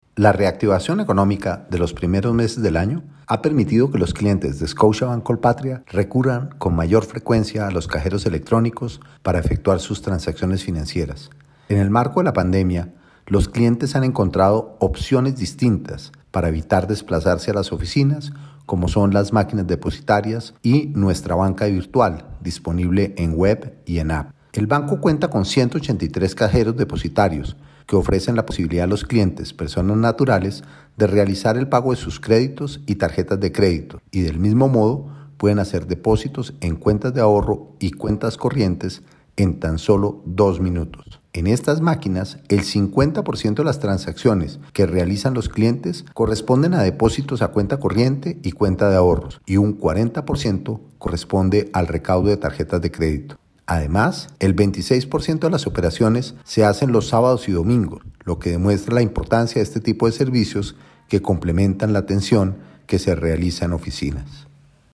Declaración